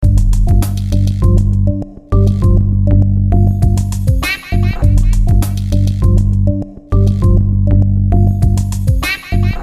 Tag: 100 bpm Funk Loops Groove Loops 1.62 MB wav Key : Unknown